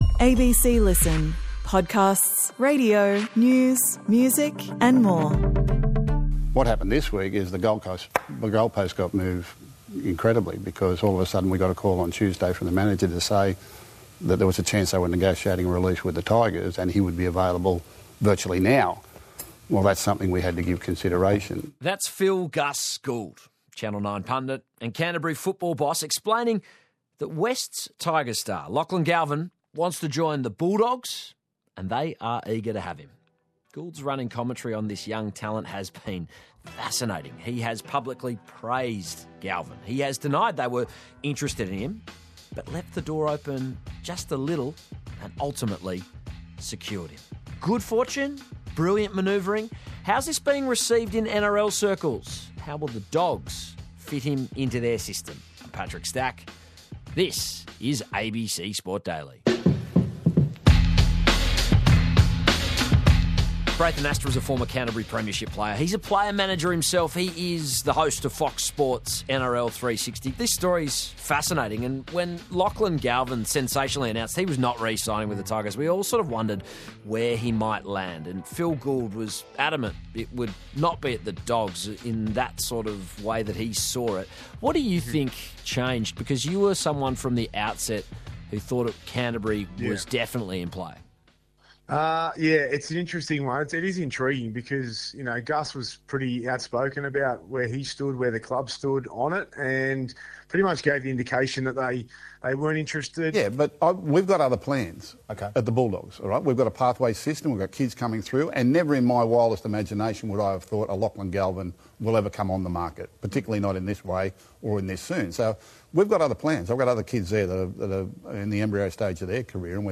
Featured: Braith Anasta, Canterbury premiership player, player manager, host Fox League.